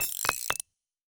Rocket_upgrade (2).wav